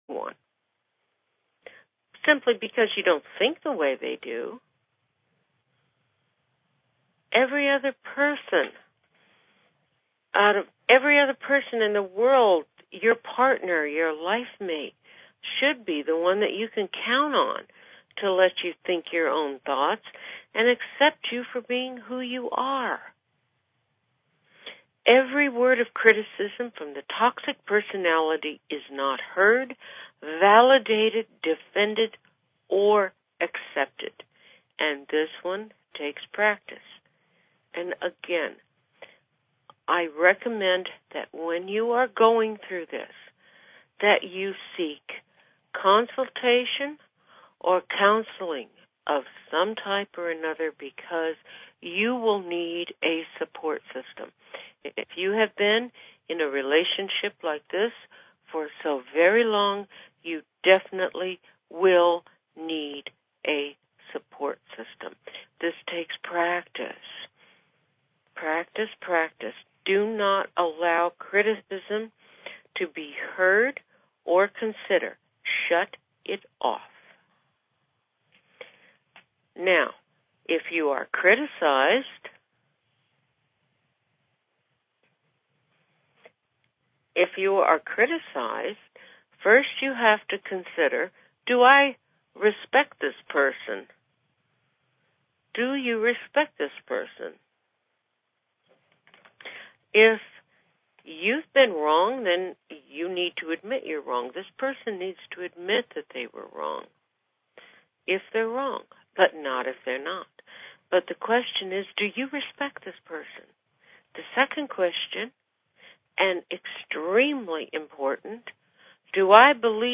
Talk Show Episode, Audio Podcast, Cyber_Shaman and Courtesy of BBS Radio on , show guests , about , categorized as